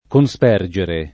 cospergere [koSp$rJere] v.; cospergo [koSp$rgo], ‑gi — pass. rem. cospersi [koSp$rSi]; part. pass. cosperso [koSp$rSo] — anche, più alla lat., conspergere [